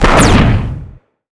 energy_blast_large_01.wav